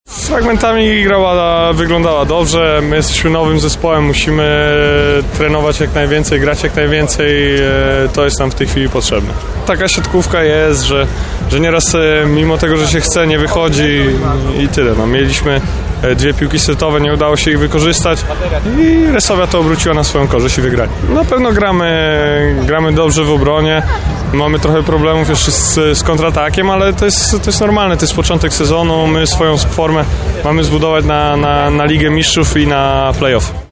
Mimo porażki grę swojej drużyny chwalił reprezentant Polski Michał Kubiak, przyjmujący Jastrzębskiego Węgla: